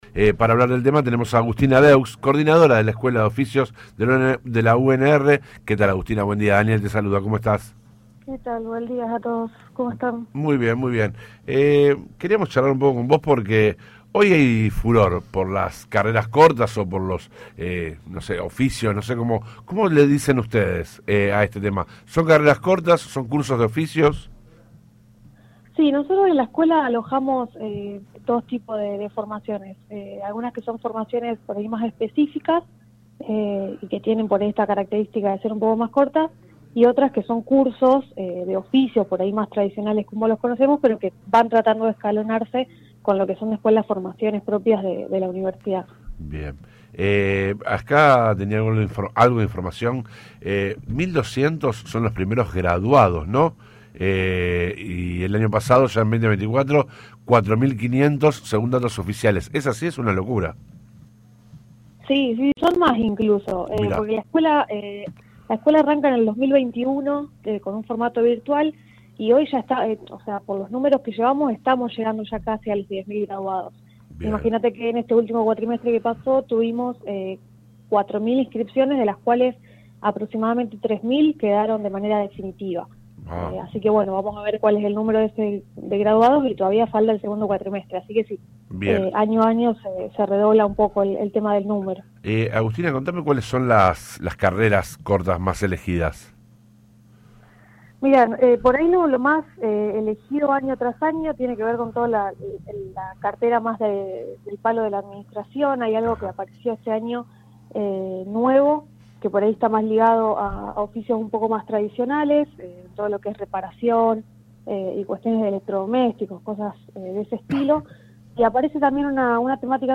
habló en el programa Digamos Todo y confirmó que ya son más de 10 mil los graduados con los que cuenta la institución.